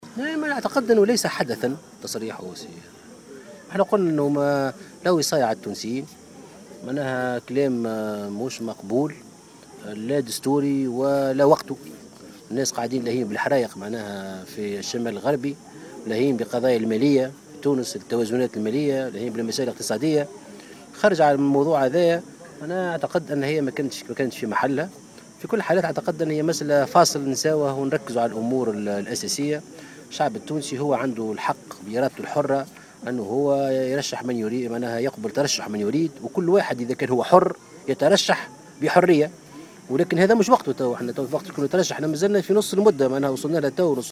وشدد مرزوق، في تصريح لمراسل الجوهرة أف أم، على هامش زيارة أداها إلى روضة آل بورقيبة بالمنستير، اليوم الخميس، على أنه "لا وصاية على التونسيين" وعلى إرادتهم في ترشيح من يشاؤون، كما أن من حق الجميع أن يترشح للانتخابات، مشيرا إلى أنه من السابق لأوانه الحديث عن انتخابات 2019.